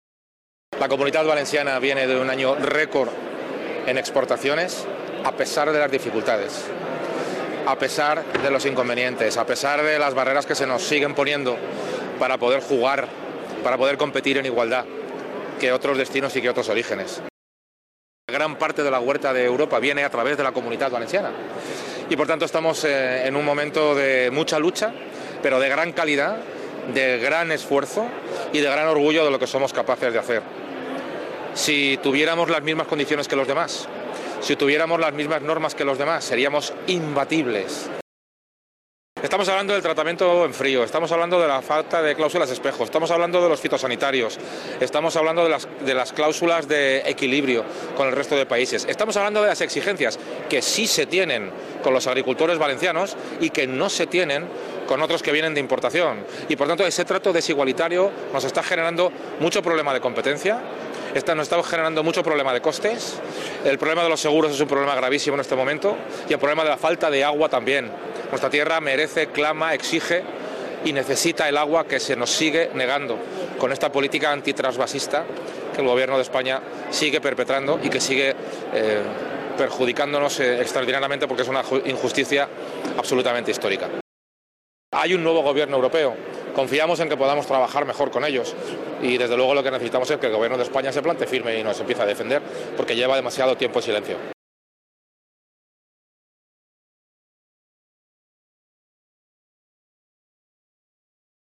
El jefe del Consell ha visitado, junto al conseller de Agricultura, Agua, Ganadería y Pesca, Miguel Barrachina, a las empresas valencianas que participan en el estand de la Comunitat Valenciana en Fruit Logistica 2025, el certamen más importante del sector de la fruta y la verdura fresca que se celebra en Berlín (Alemania).